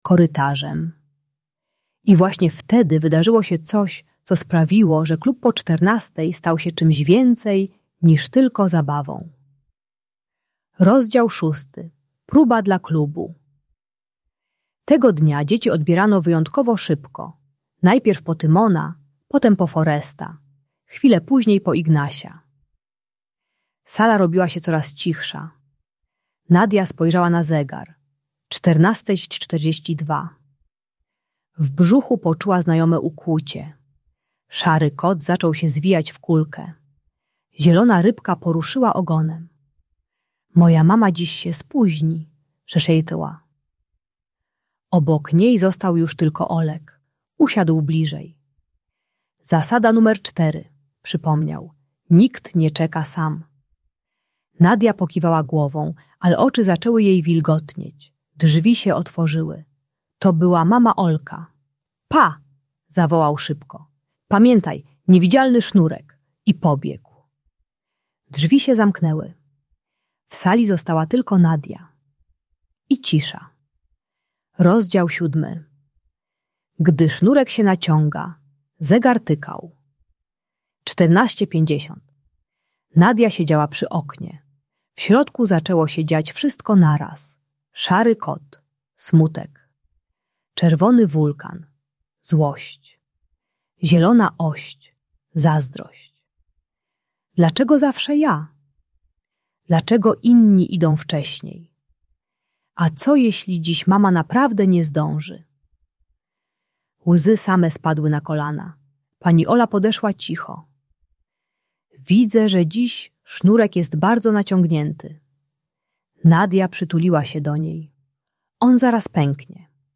Klub Po Czternastej - Lęk wycofanie | Audiobajka